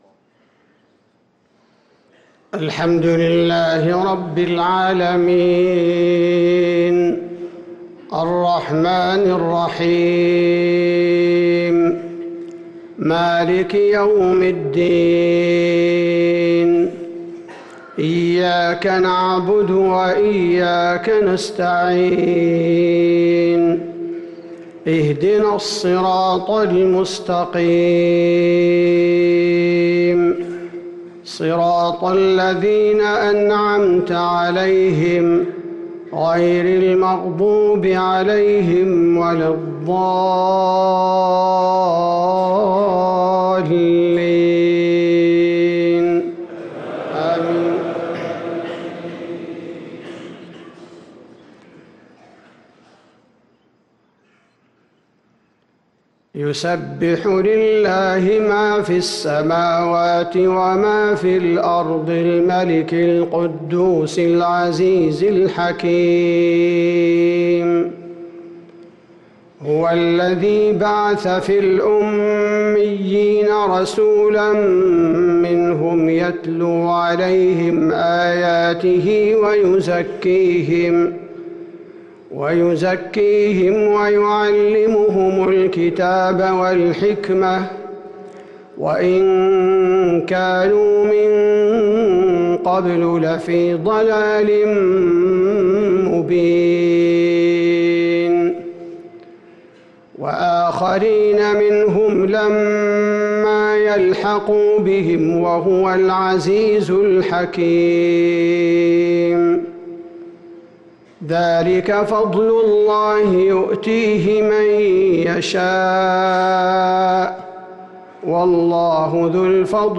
صلاة الفجر للقارئ عبدالباري الثبيتي 12 شوال 1444 هـ
تِلَاوَات الْحَرَمَيْن .